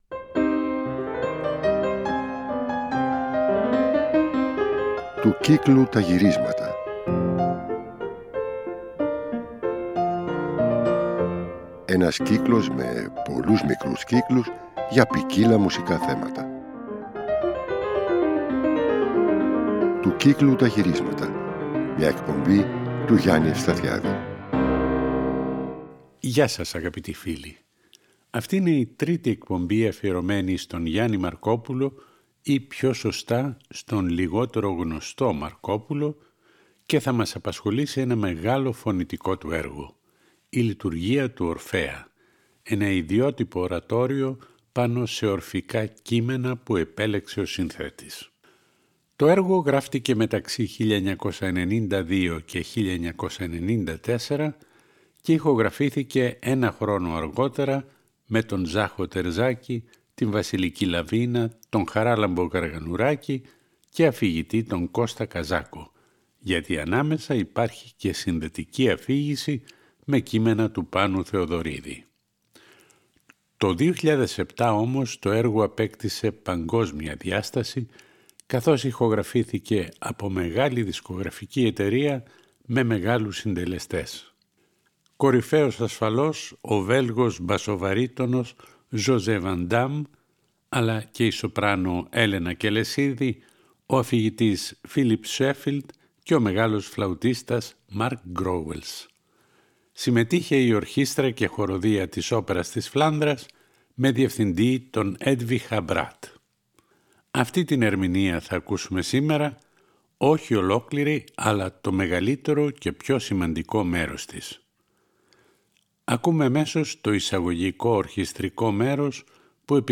Στις εκπομπές αυτές θα ακουστούν και οι λιγότερο γνωστές συνθέσεις αυτού του πολύμορφου και πολυφωνικού έργου.
Τραγούδια, κινηματογραφικές μουσικές, ραψωδίες, πυρρίχιοι χοροί, κοντσέρτα, μουσική δωματίου, ορατόρια, όπερες ακόμα και ανέκδοτα έργα του.